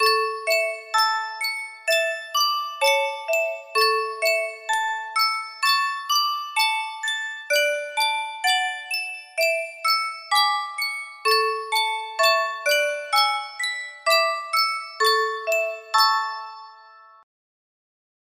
Sankyo Music Box - Christ Was Born on Christmas Day JXG music box melody
Full range 60